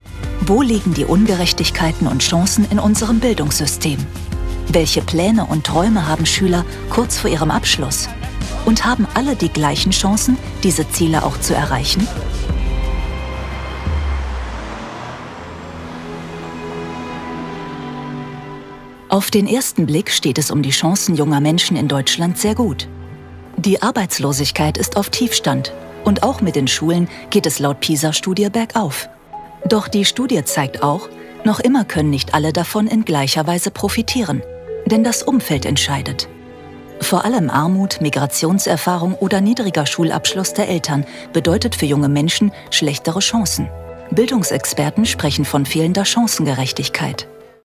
Werbung - McDonalds